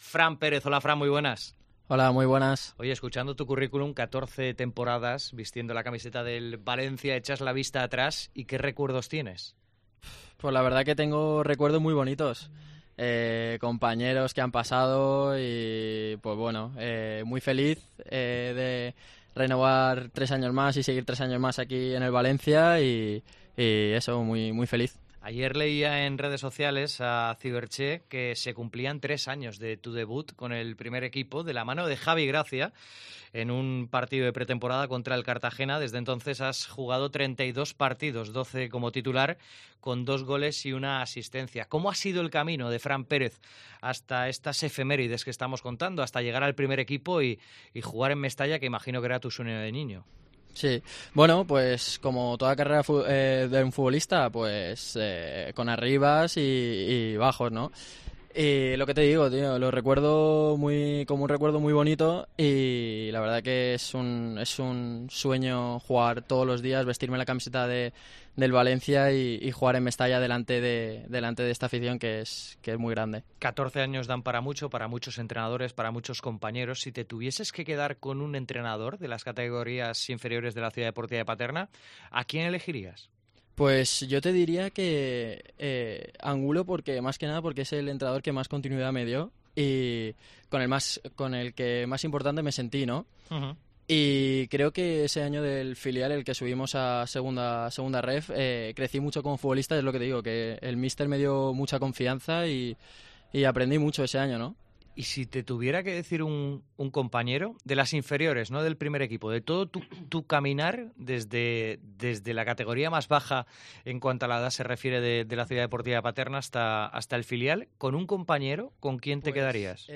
El canterano nos habla desde la concentración de la selecció española sub21. El extremo nos habla de la figura de su padre, de las dudas del verano y de su renovación hasta el 2026